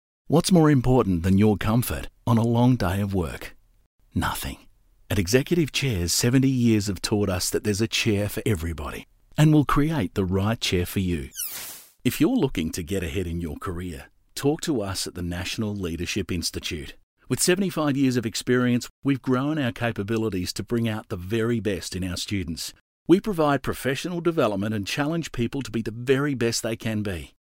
I guess you can call me ‘the everyday bloke’.
• Natural